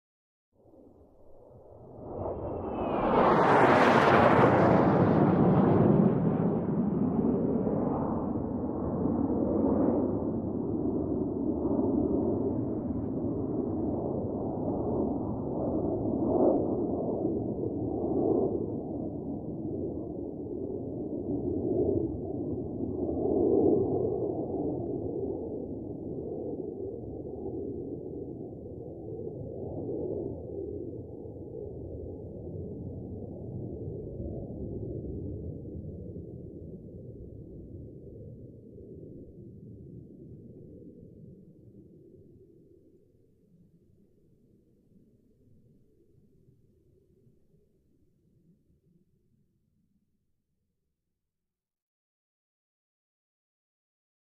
F-5: By High; F-5 By With High Descending Whistle, Long Distant Roar Of Away. Medium To Distant Perspective. Jet.